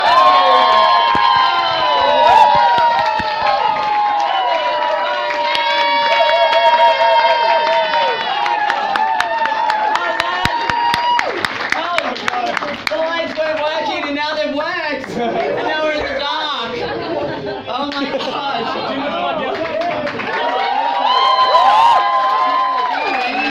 just some applause